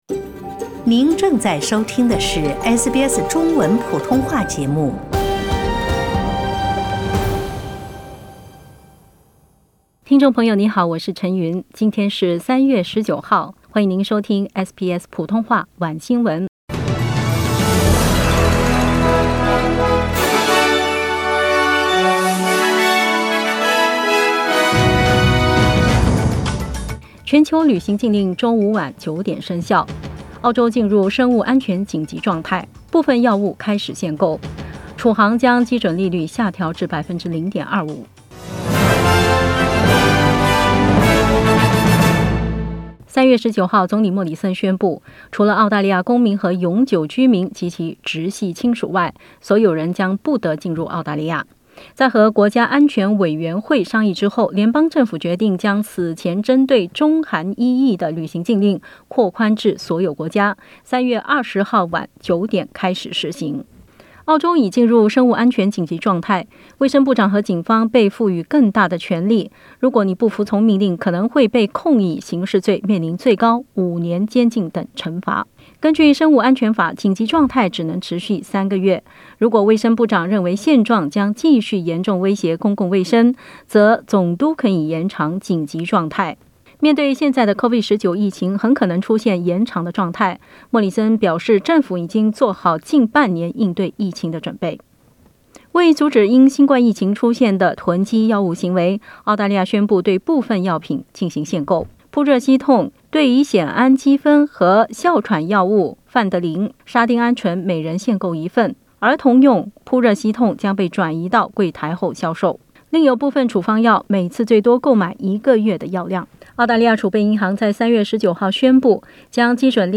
SBS晚新闻（3月19日）